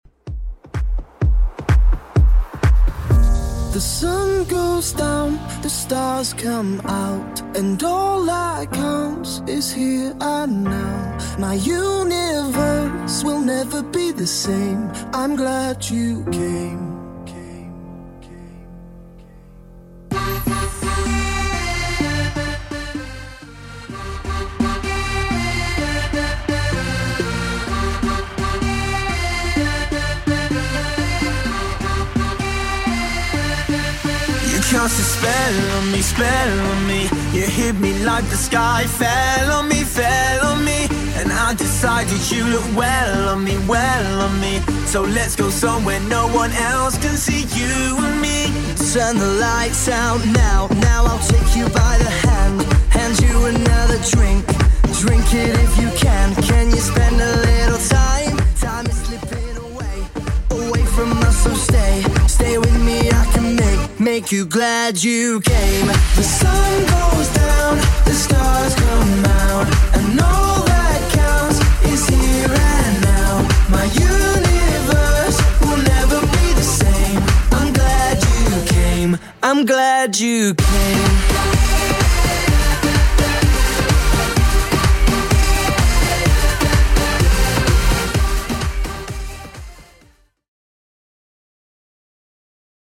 Genre: 90's
BPM: 121